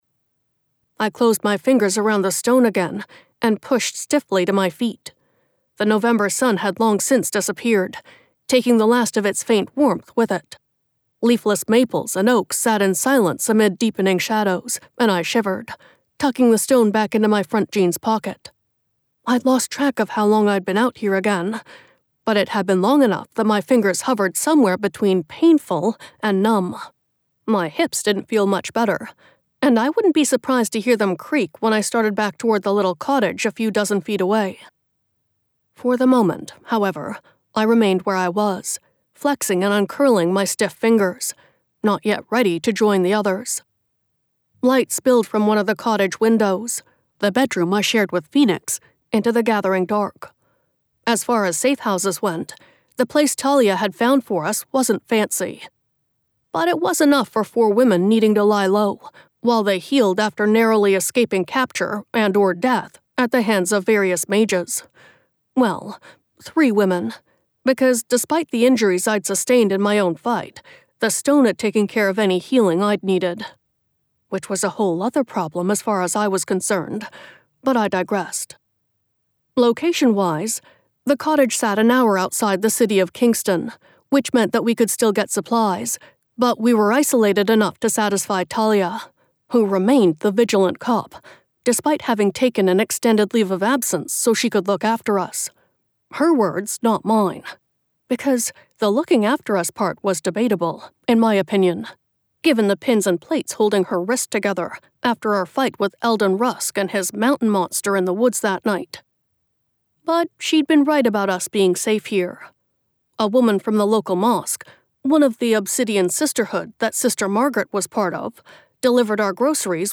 Narrator:
Related Audiobooks